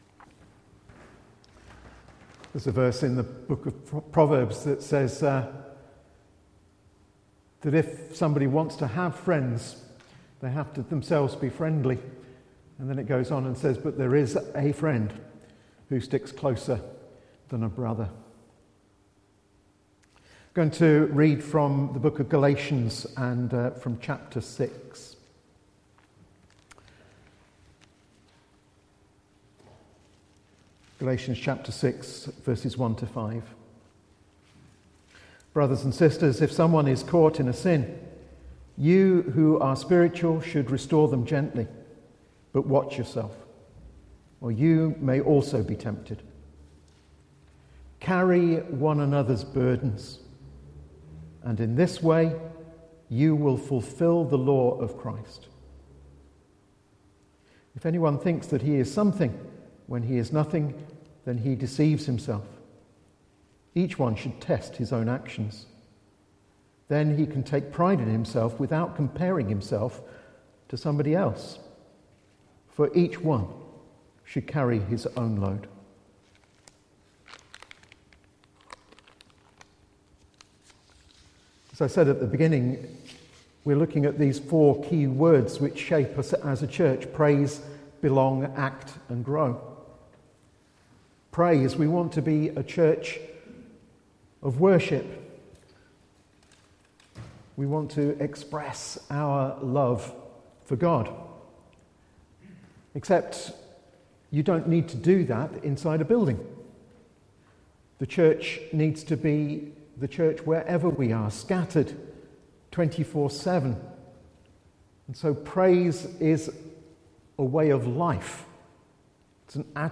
A message from the service
From Service: "10.30am Service"